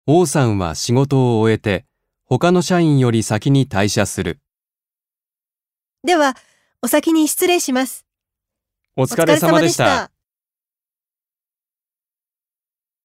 1.1. 会話（社内での日常の挨拶）